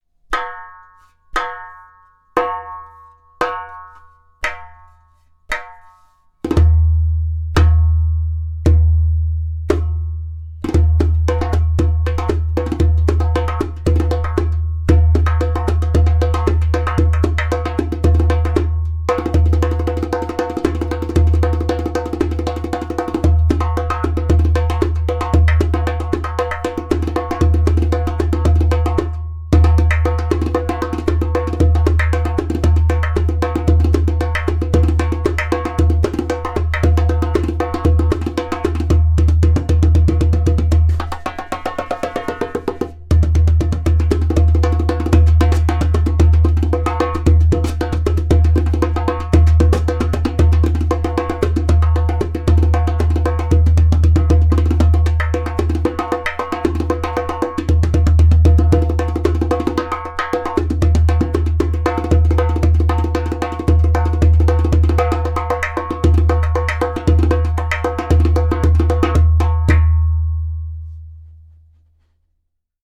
115bpm
• High pitch tak paired with deep bass.
• Strong and super easy to produce clay kik (click) sound
• Beautiful harmonic overtones.